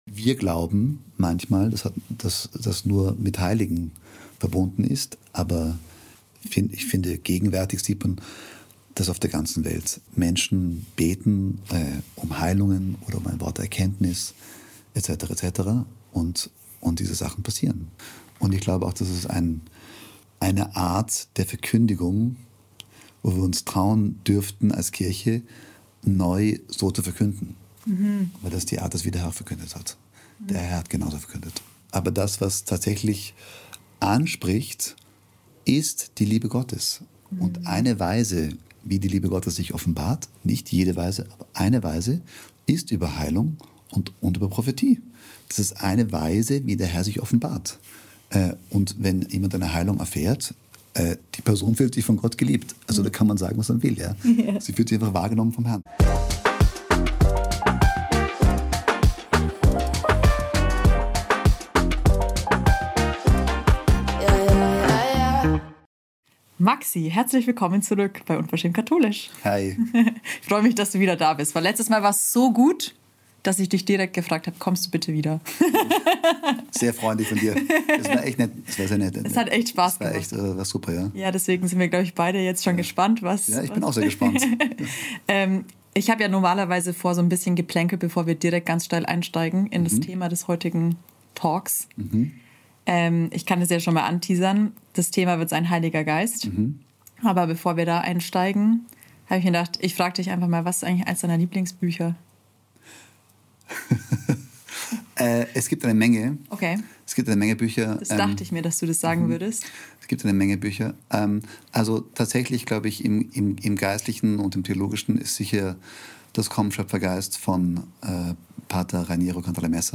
In diesem tiefgehenden Gespräch reden wir über den Heiligen Geist und gehen den großen Fragen nach: Wer ist der Heilige Geist und wie finden wir ihn in der Bibel? Wie kann ich mehr vom Heiligen Geist erleben?